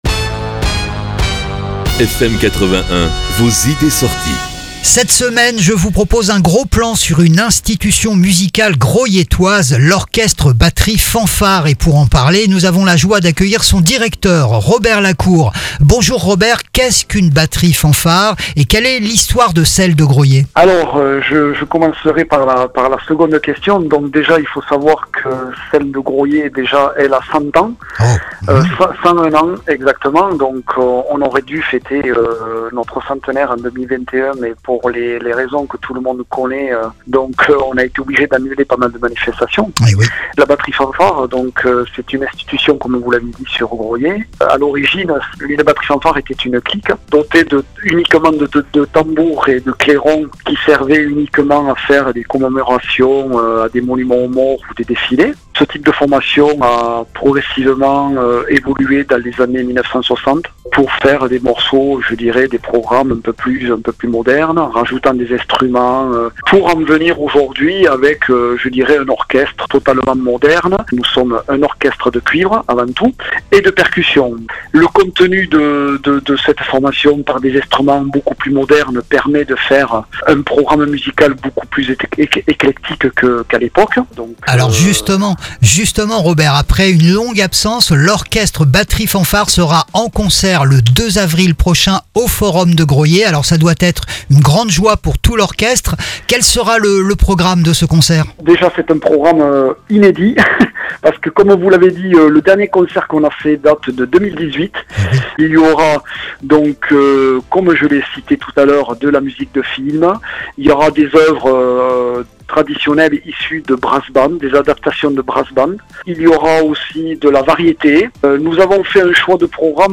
L'Orchestre-Batterie-Fanfare de Graulhet en concert !